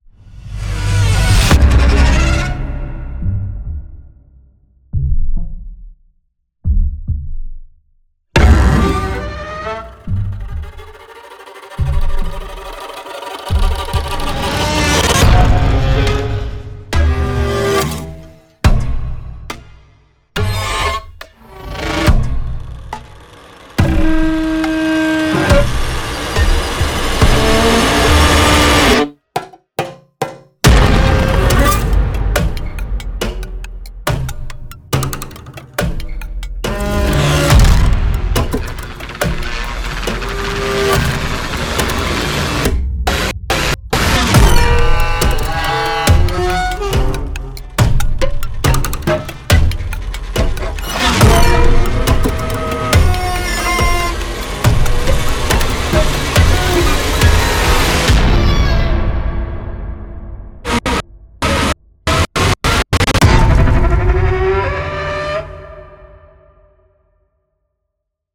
Tense atmospheric sections